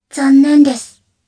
Kara-Vox_Sad_jp.wav